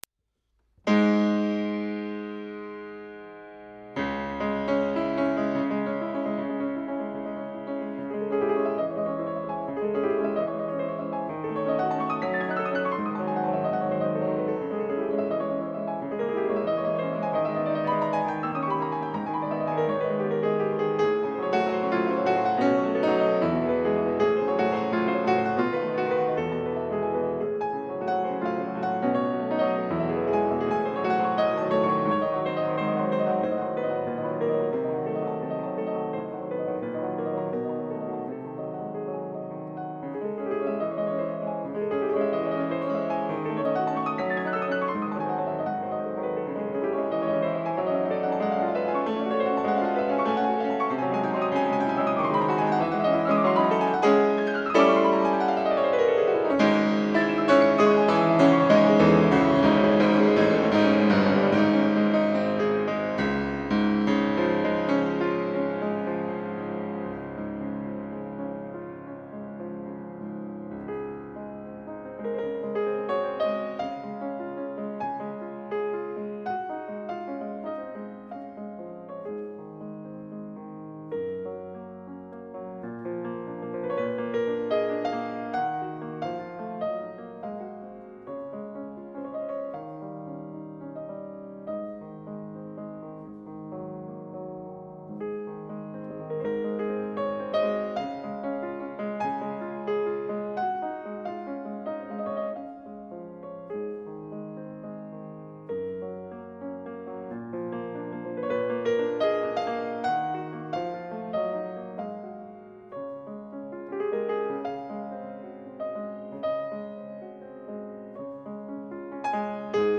即興的なインスピレーション感が強く透明感ある音階やアルペジオが印象的なメロディです。
聴いているとただただ滑らかで優雅ですが、幻想感の元は、徹底した左右のリズムの独立です。
その規則正しい音並びの中に、美しい優雅な旋律が浮き出してくるような構造になっています。